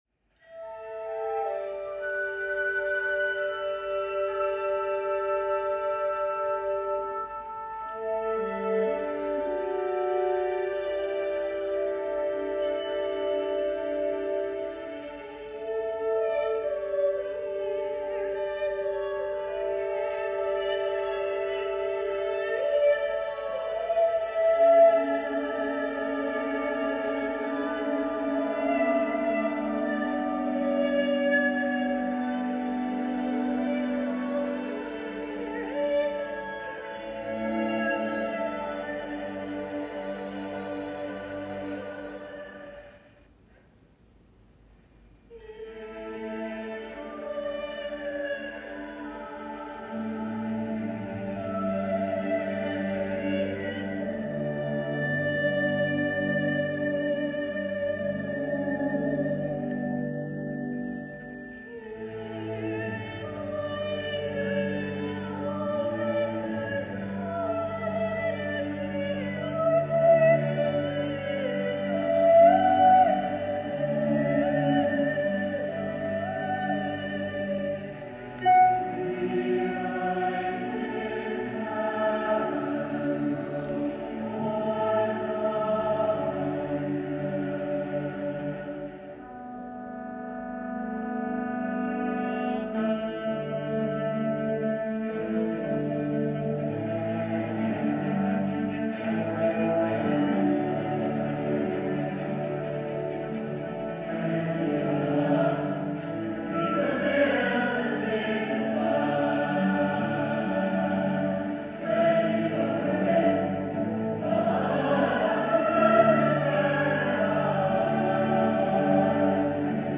An elegant and joyful welcoming to the season of Christmas.